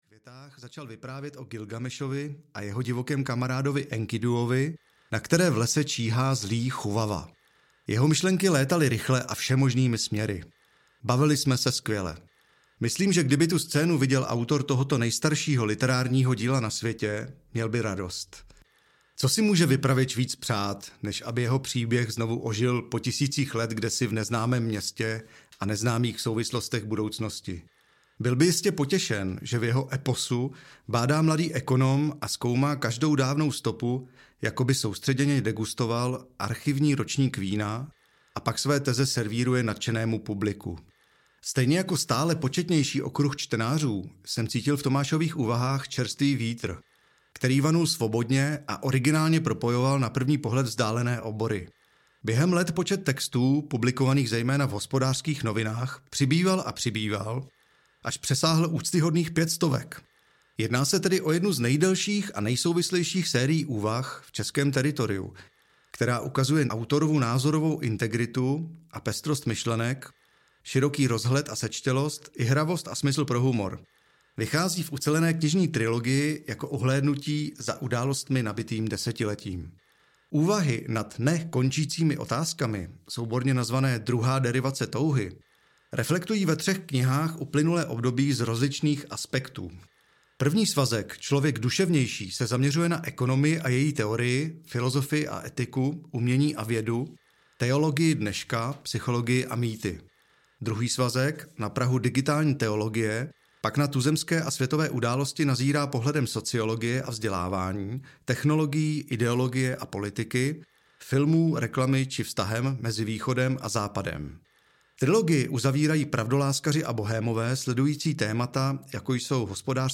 Druhá derivace touhy: Člověk duše-vnější audiokniha
Ukázka z knihy
• InterpretTomáš Sedláček, Martin Myšička